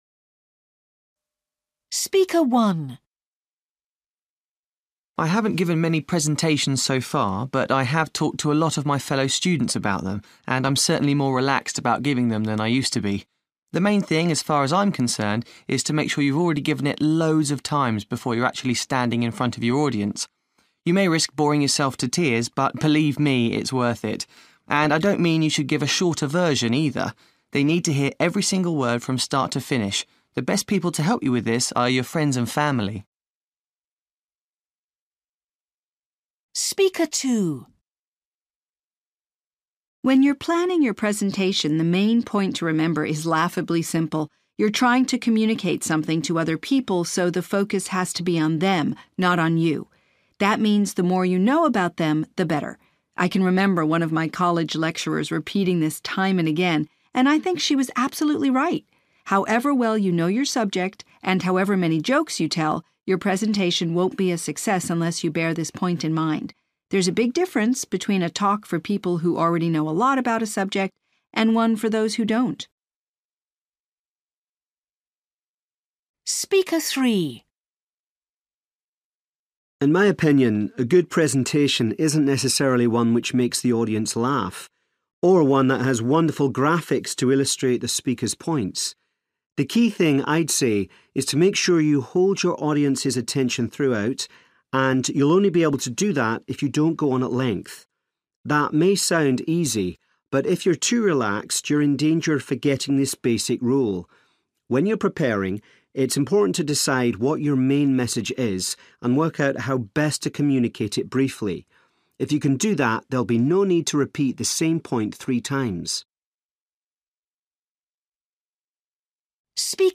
You will hear five short extracts in which people are talking about how to give good presentations.